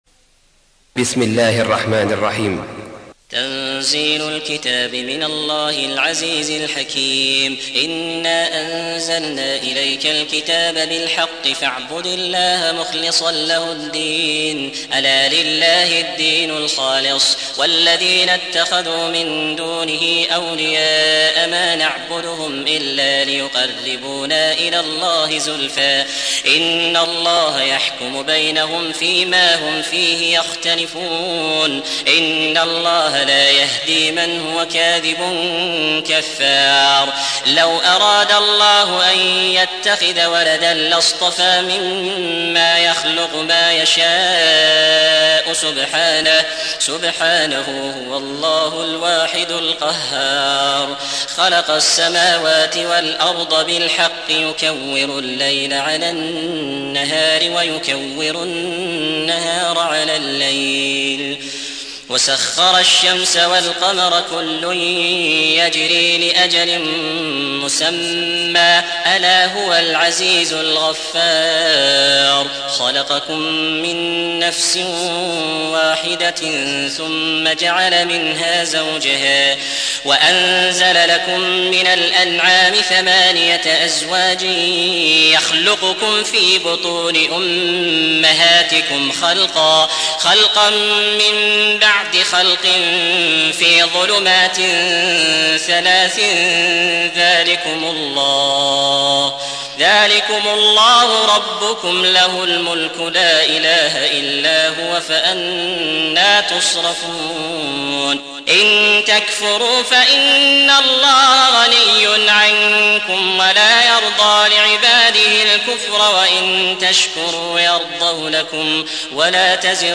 39. سورة الزمر / القارئ